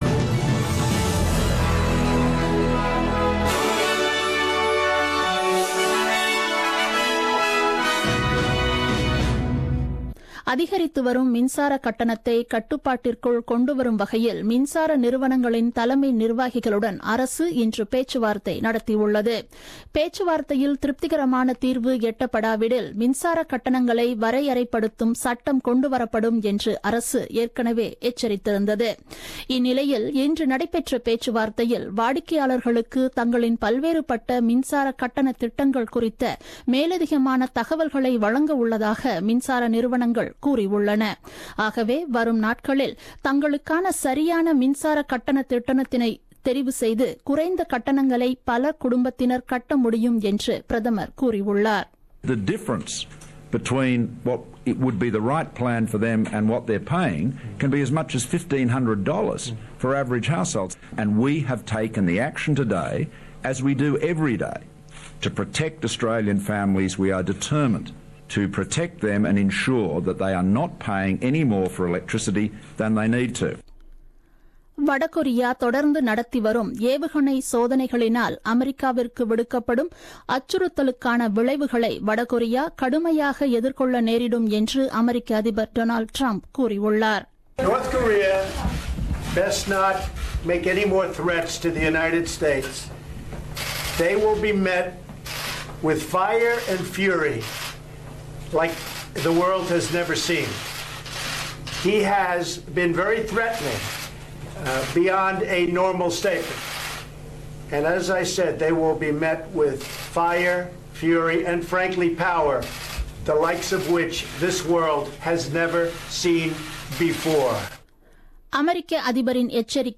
The news bulletin broadcasted on 09th August 2017 at 8pm.